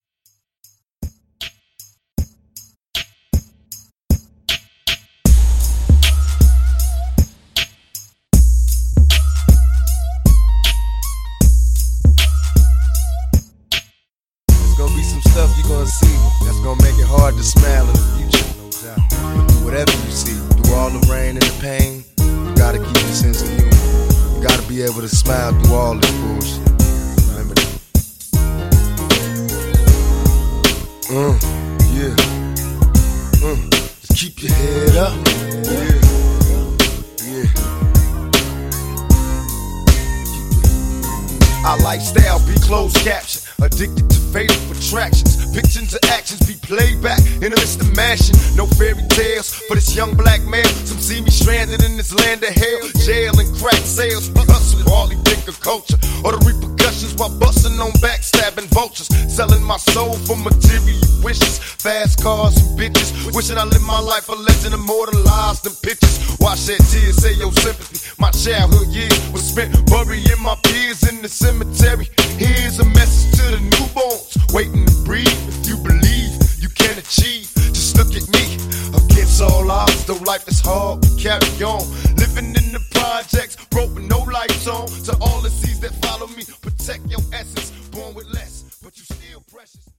Genres: HIPHOP , R & B Version: Clean BPM: 80 Time